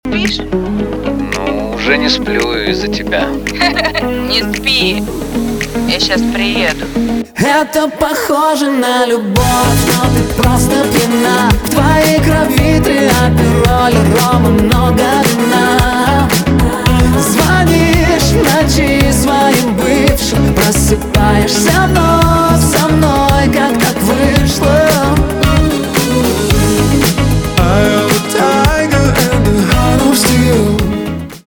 поп
битовые , труба